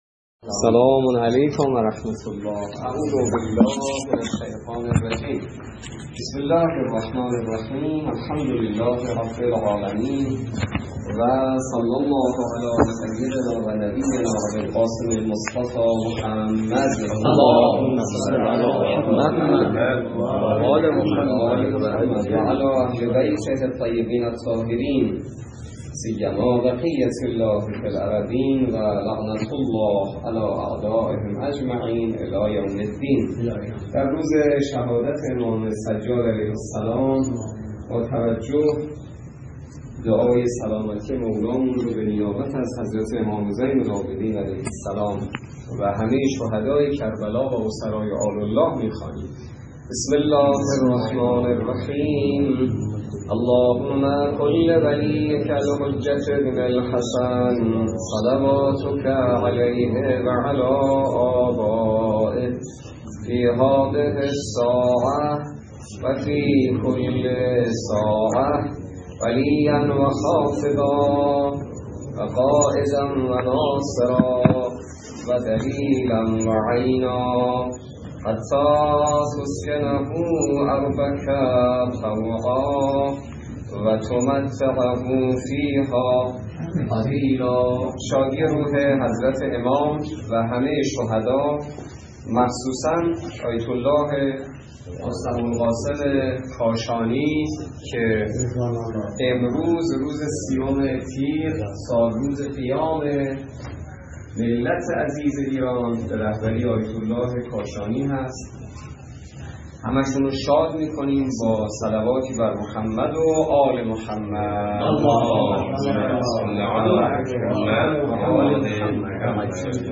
روایات ابتدای درس فقه